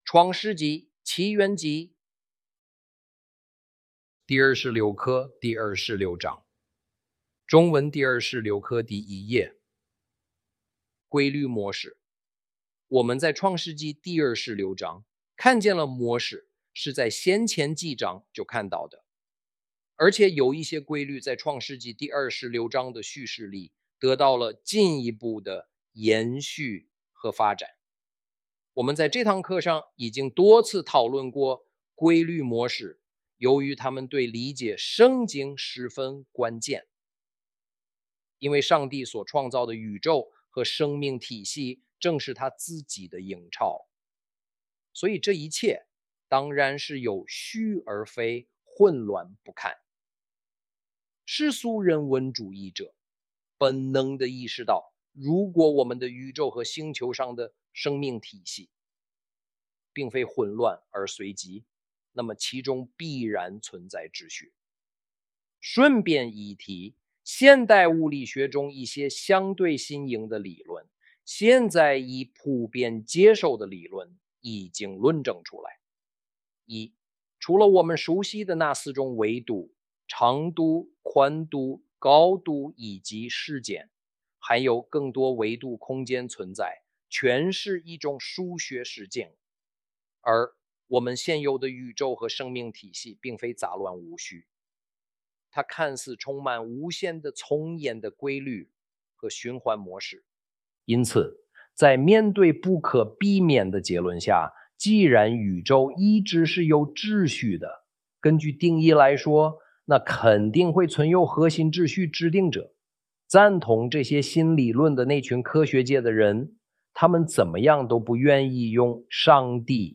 創世紀(起源紀) 第二十六課-第二十六章 中文第26課第1頁 Explore Torah and the New Testament with historical context. Video, audio and textual lessons